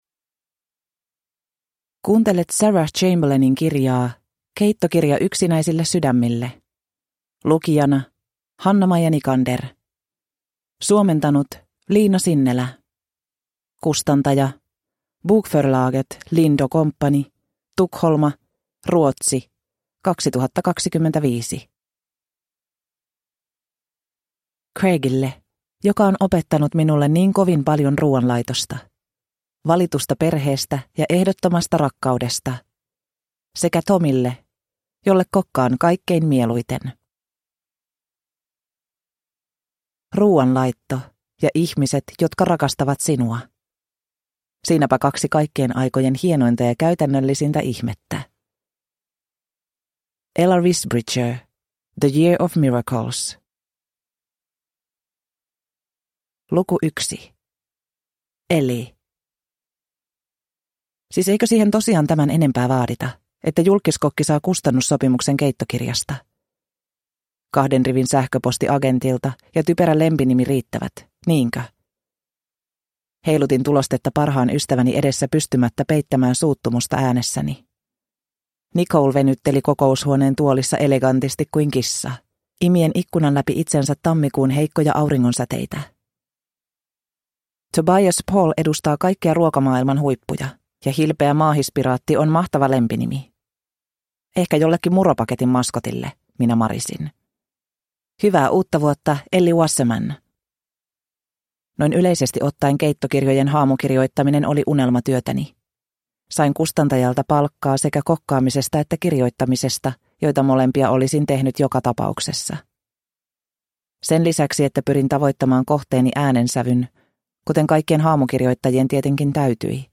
Keittokirja yksinäisille sydämille (ljudbok) av Sarah Chamberlain